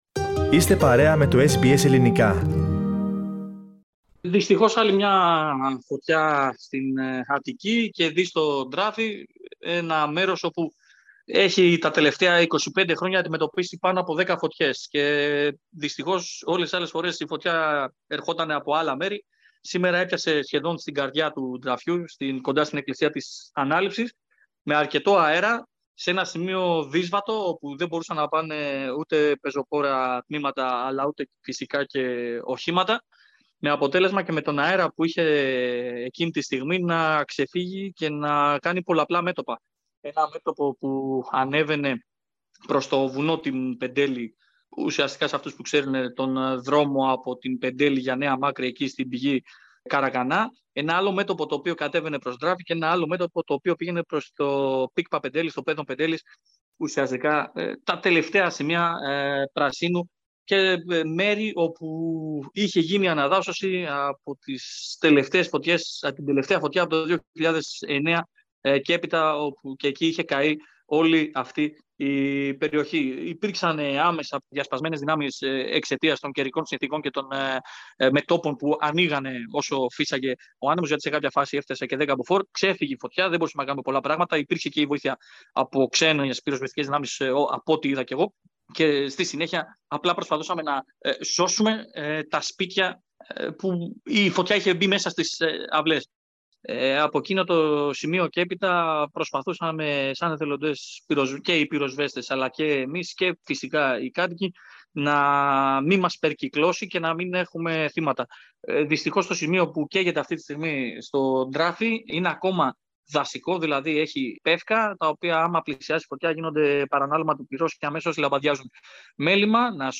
The Acropolis and Parthenon, bearing witness to civilisational history, quietly watched another summer of wildfires that raged in their backdrop. A local journalist who also volunteers as a firefighter, narrates the scene.